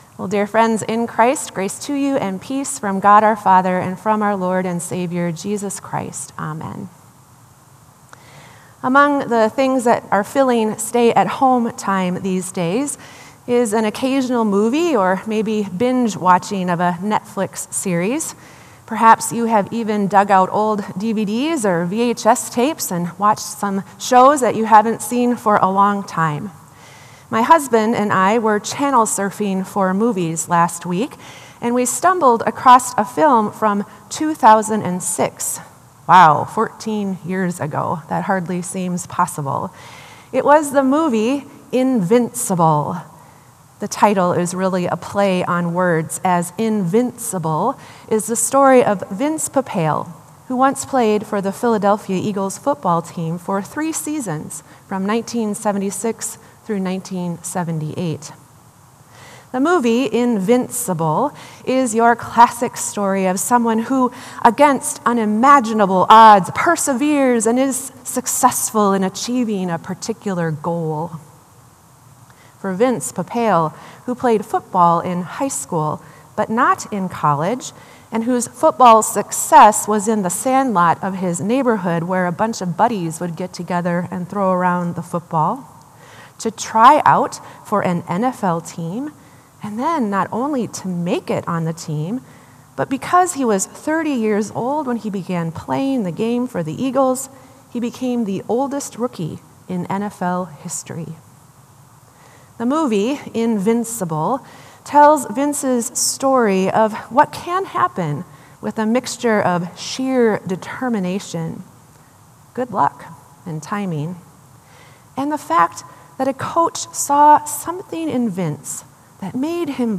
Sermon “Perseverance To Hope”